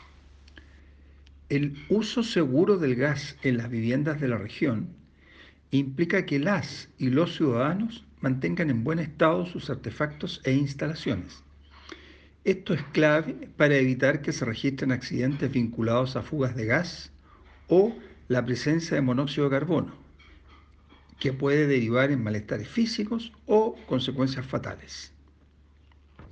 Audio: Ricardo Miranda, Director Regional de SEC O’Higgins